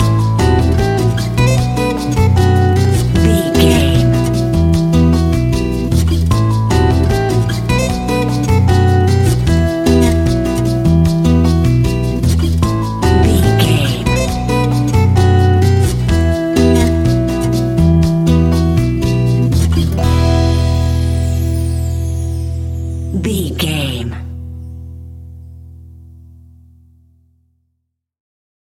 Uplifting
Aeolian/Minor
maracas
percussion spanish guitar
latin guitar